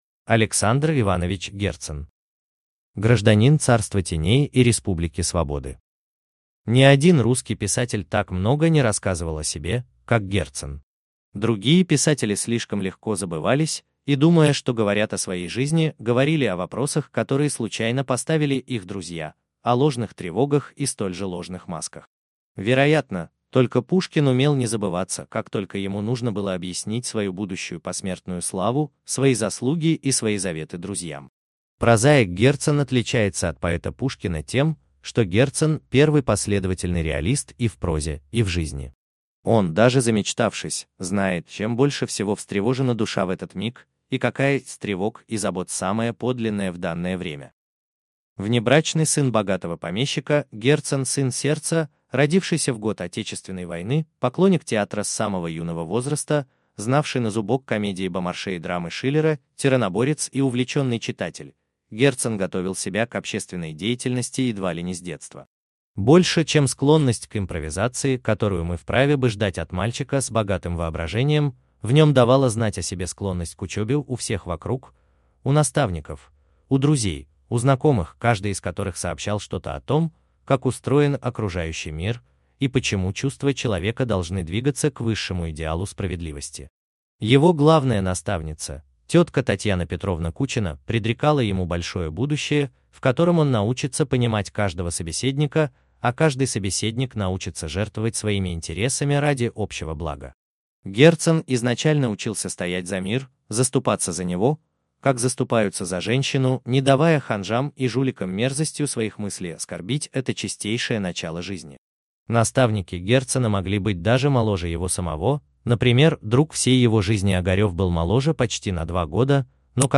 Аудиокнига Об искусстве | Библиотека аудиокниг